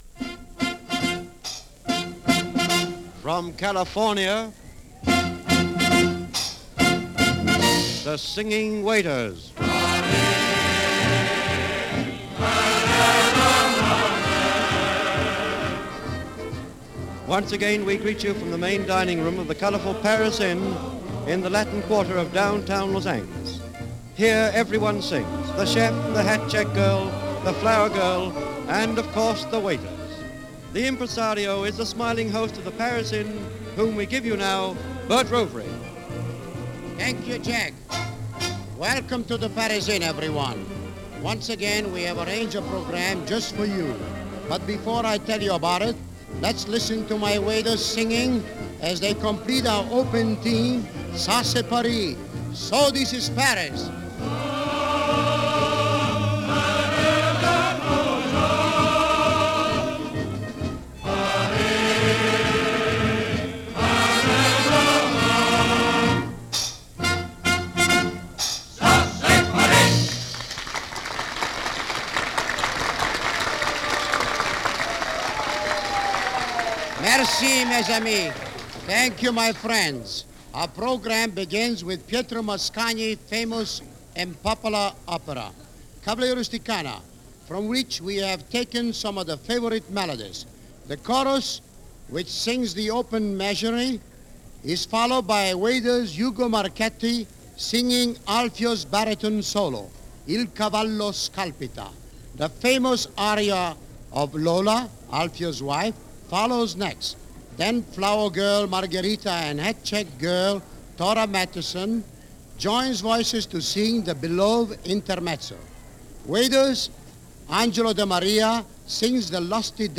L.A. Nightlife - 1937 - Singing Waiters And Everything - Past Daily Archeology
Paris Inn – Downtown Los Angeles – April 14, 1937 – KNX Radio – Gordon Skene Sound Collection –